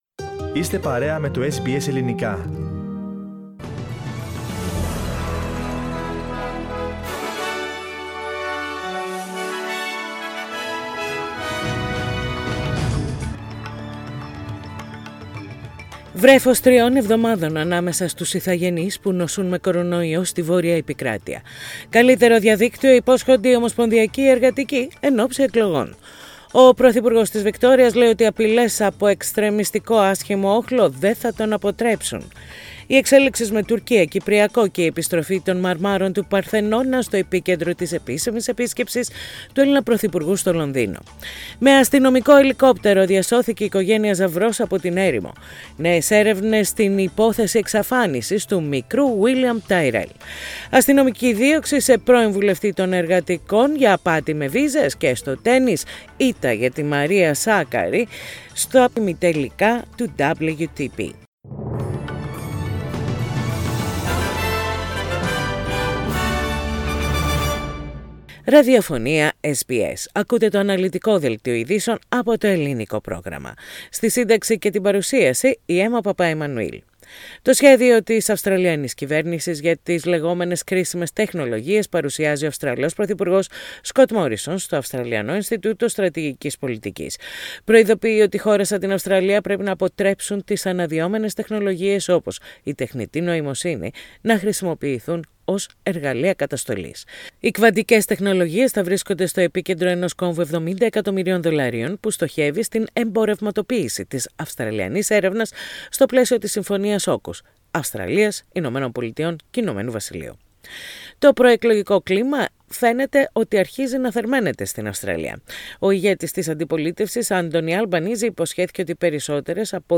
Ειδήσεις στα Ελληνικά - Τετάρτη 17.11.21
Πατήστε play στο podcast που συνοδεύει την αρχική φωτογραφία για να ακούσετε το δελτίο ειδήσεων.